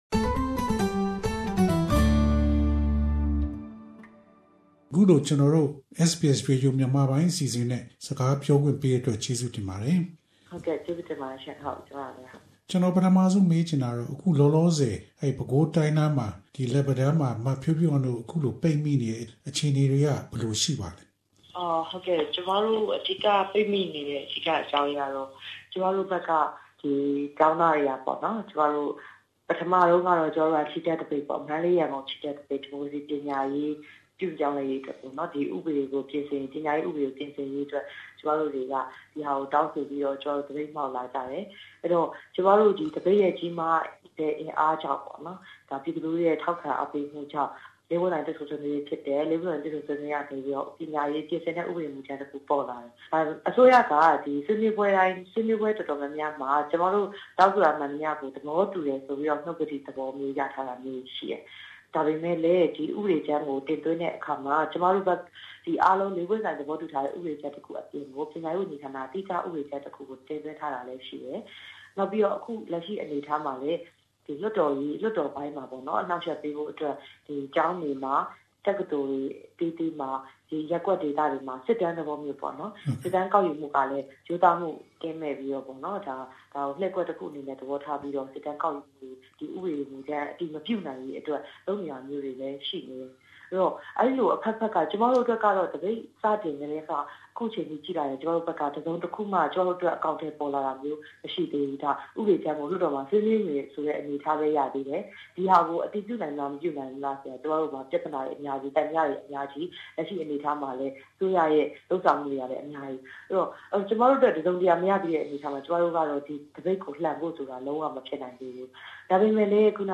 This is one of the selected interviews from 2015 program highlights